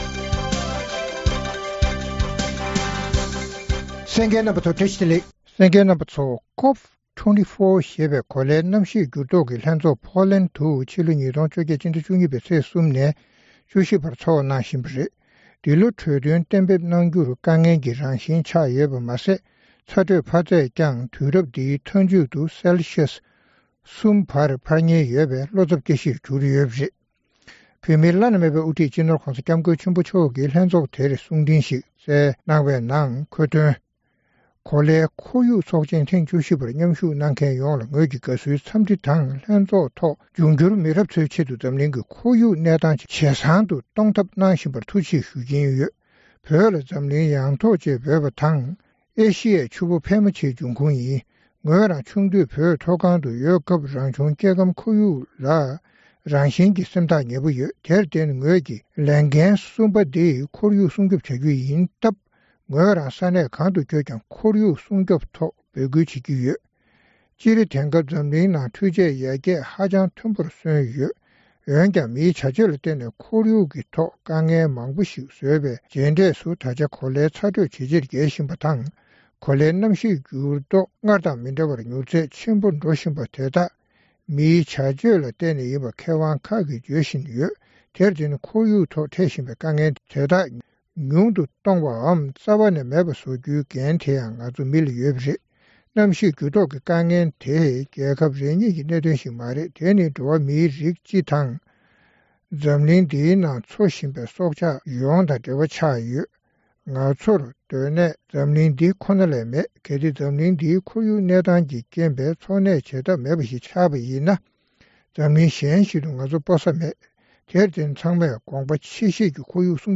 རྩོམ་སྒྲིག་པའི་གླེང་སྟེགས་ཞེས་པའི་ལེ་ཚན་ནང་། འཛམ་གླིང་མཉམ་སྦྲེལ་རྒྱལ་ཚོགས་ཀྱི་ཁོར་ཡུག་ལས་འཆར་སྡེ་ཚན་དང་། རྒྱལ་སྤྱིའི་གནམ་གཤིས་བསྒྱུར་ལྡོག་ལྷན་ཚོགས། ཨ་རི་གཞུང་གི་གནམ་གཤིས་བརྟག་དཔྱད་ཚན་པ་བཅས་ཀྱིས་བཏོན་པའི་འདི་ལོའི་གནམ་གཤིས་སྙན་ཐོའི་ནང་དོན་སྐོར་རྩོམ་སྒྲིག་འགན་འཛིན་རྣམ་པས་བགྲོ་གླེང་གནང་བ་གསན་རོགས་གནང་།